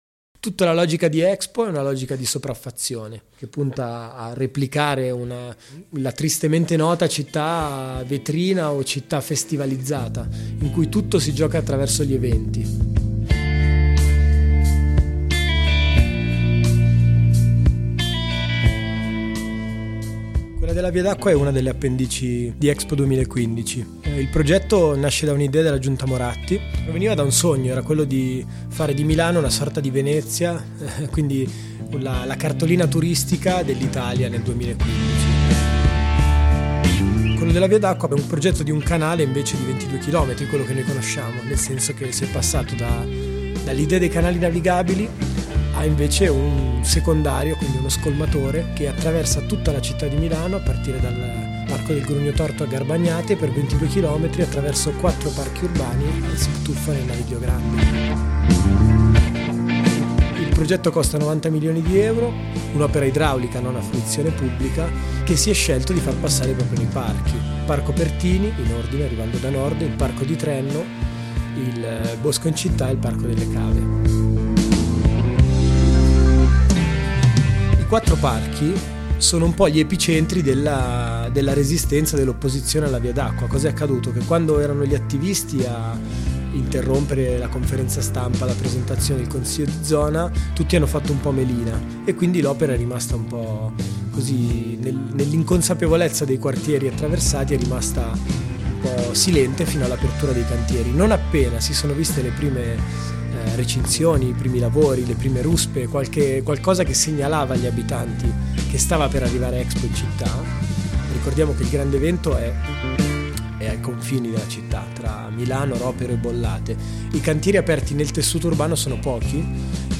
Anche in vista del corteo previsto per il 16 febbraio 2014, ci siamo fatti raccontare quest’esperienza da un compagno impegnato nell’opposizione a Expo 2015.